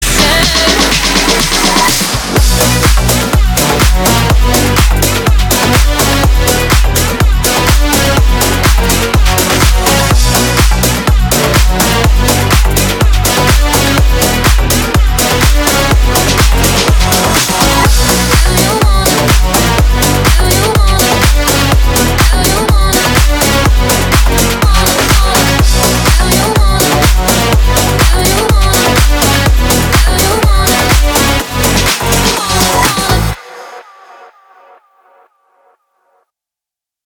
dance
Electronic
Club House
чувственные
красивый женский голос
быстрые